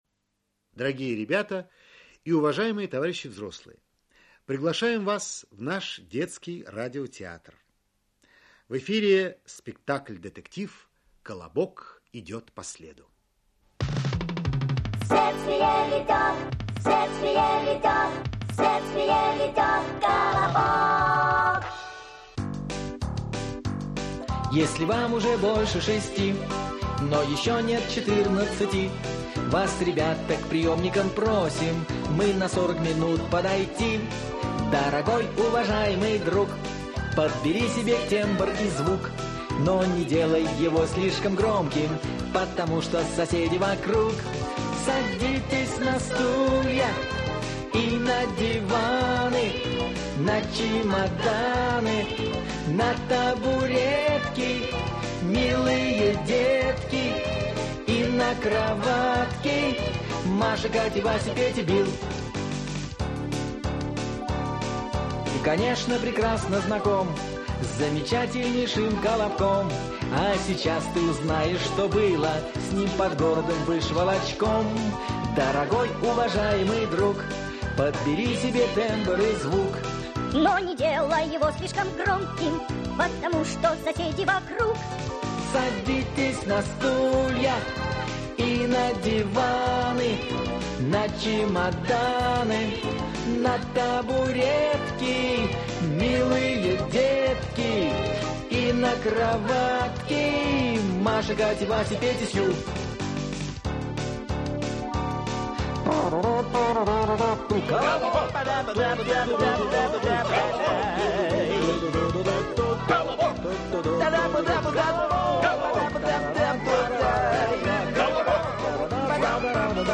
Следствие 4-е. Операция «Браконьер» (спектакль) Автор Эдуард Успенский Читает аудиокнигу Актерский коллектив.